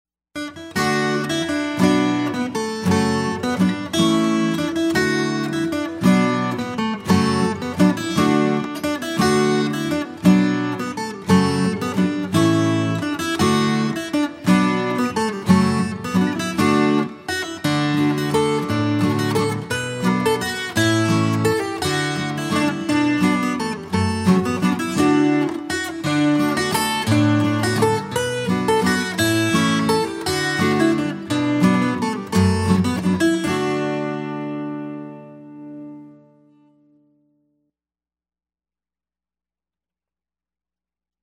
DIGITAL SHEET MUSIC - FLATPICK/PLECTRUM GUITAR SOLO
Scottish, Favorite Guitar Picking Tune, Jamming